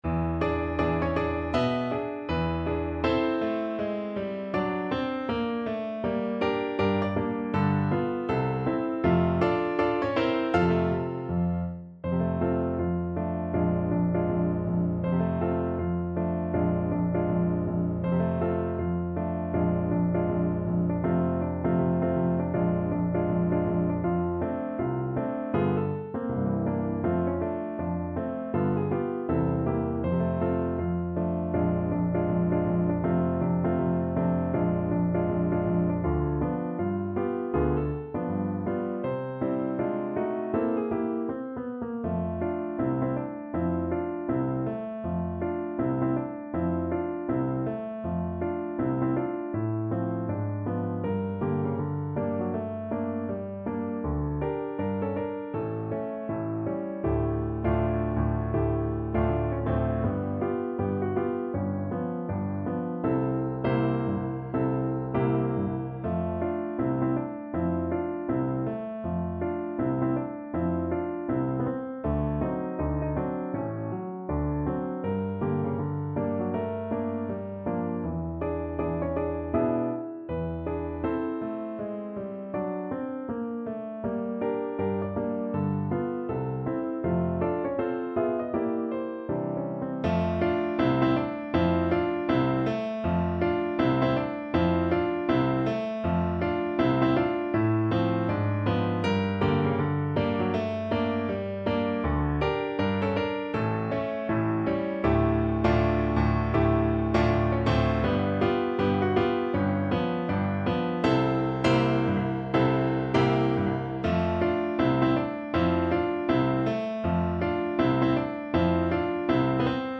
Jazz
~ = 160 Moderato